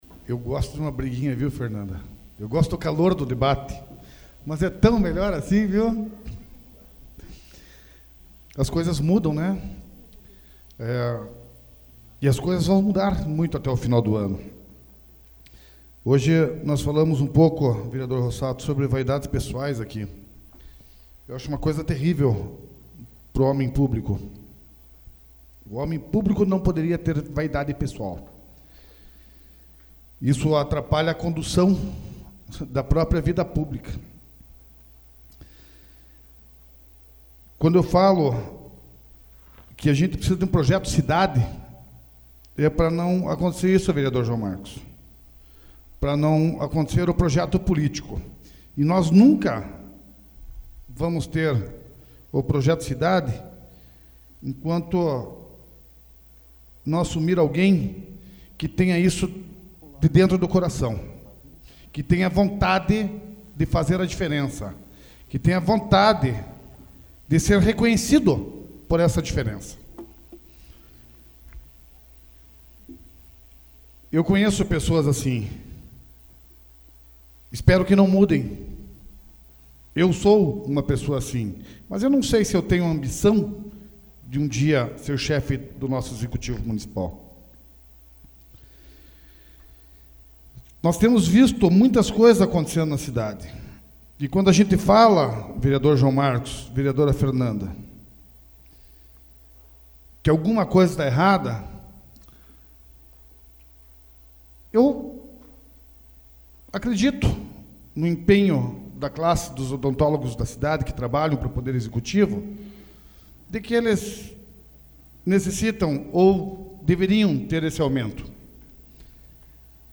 SAPL - Câmara Municipal de Campo Largo - PR
Explicações pessoais AVULSO 08/04/2014 Junior Torres